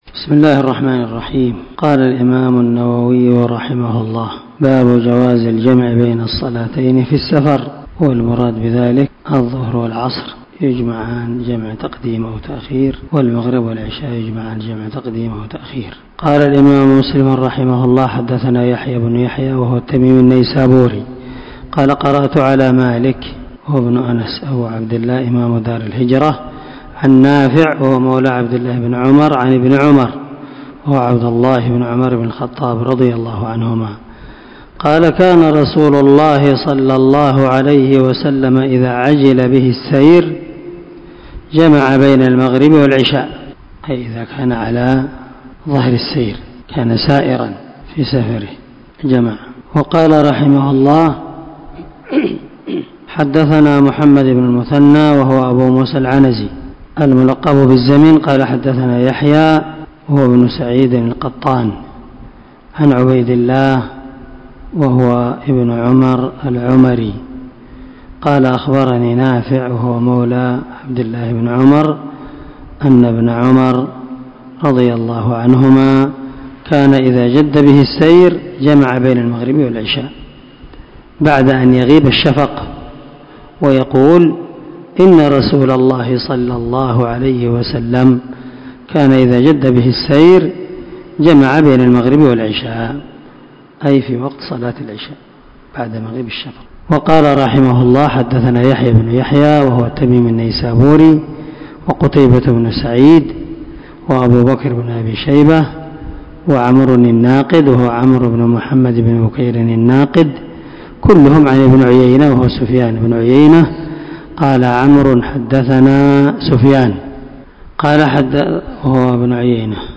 439الدرس 7 من شرح كتاب صلاة المسافر وقصرها حديث رقم ( 703 – 704 ) من صحيح مسلم
دار الحديث- المَحاوِلة- الصبيحة.